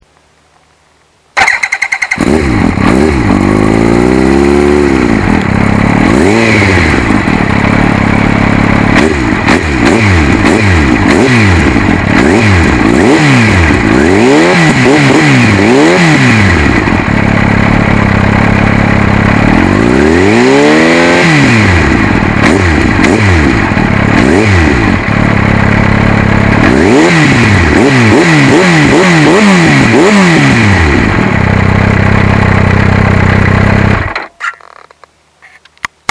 yamaha_r1_02.mp3